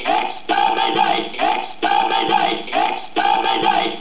Click on the Dalek for a killer sound